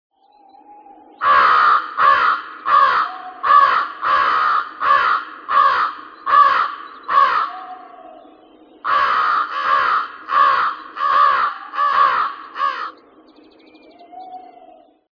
Raben
raben.mp3